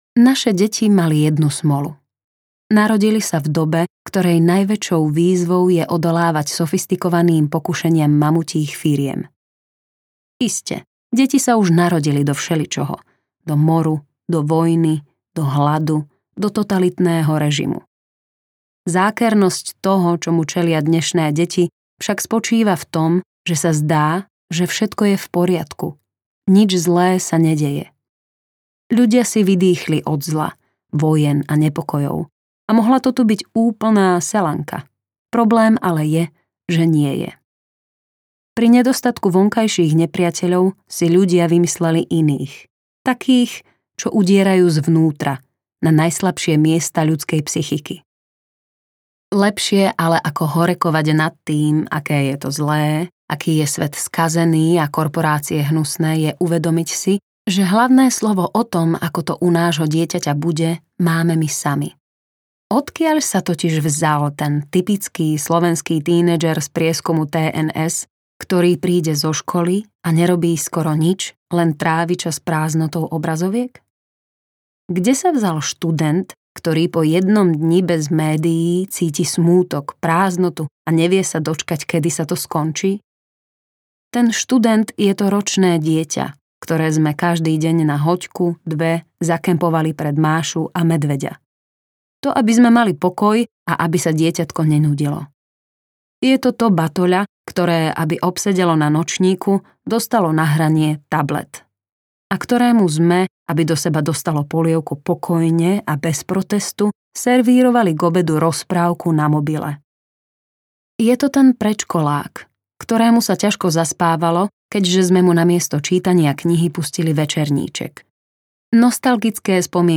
Klub nerozbitných detí audiokniha
Ukázka z knihy